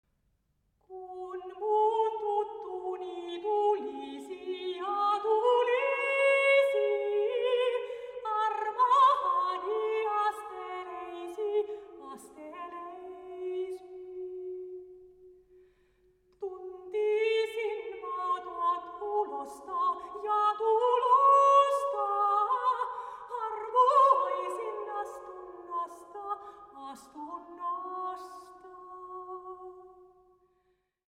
sopraano, dulcimer, sinfonia ja 5-kielinen kantele
Kalevalainen runolaulu: